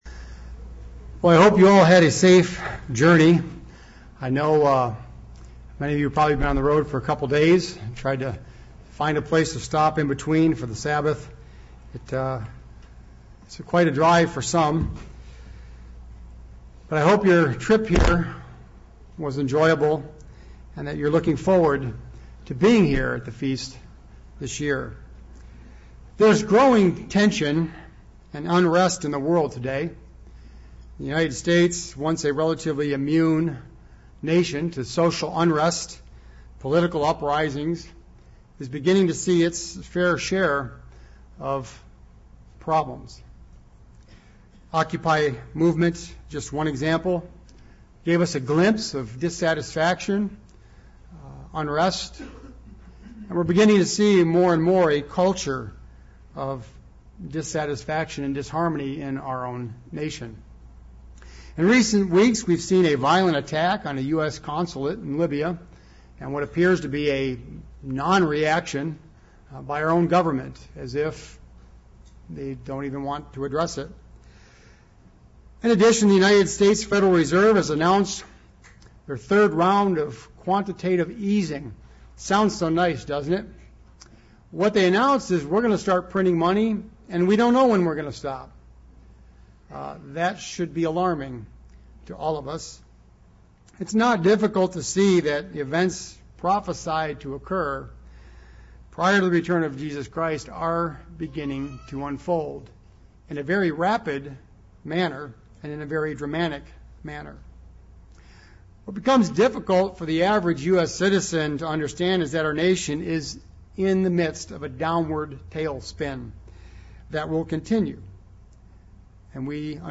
This sermon was given at the Lake George, New York 2012 Feast site.